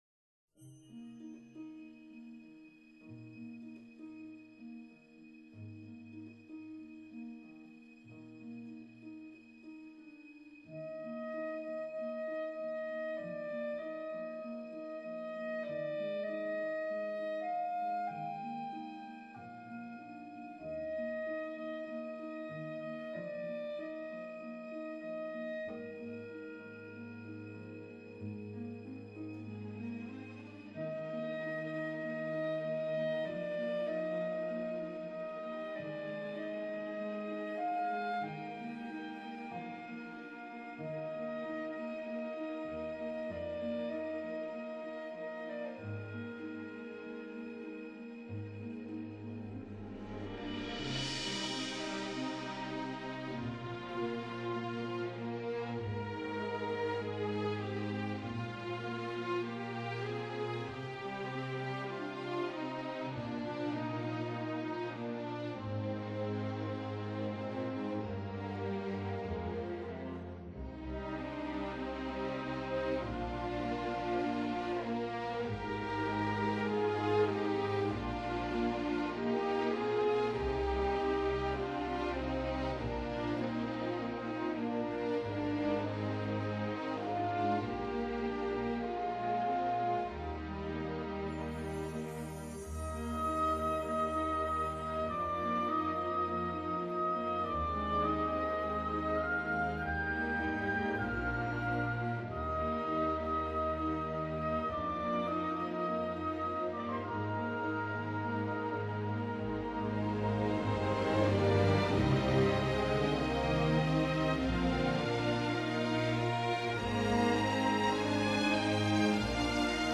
Genre: Classical Music, Soundtrack